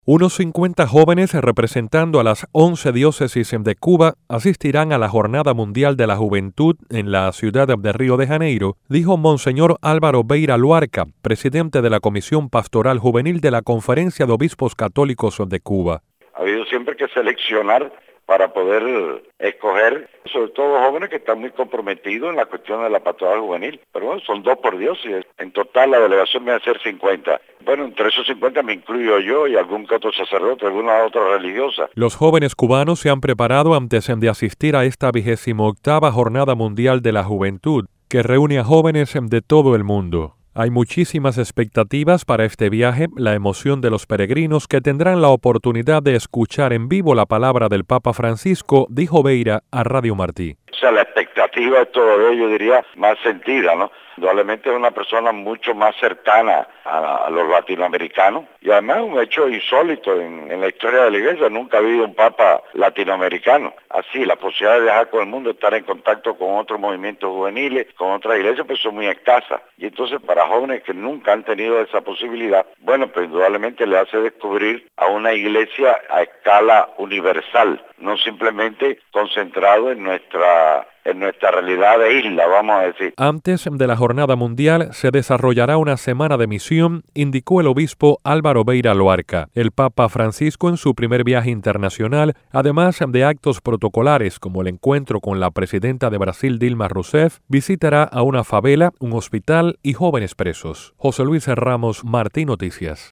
Jóvenes católicos cubanos se preparan para asistir a la Jornada Mundial de la Juventud que se efectuará en Brasil. El obispo Alvaro Beyra Luarca, que encabeza la delegación cubana dio detalles a martinoticias.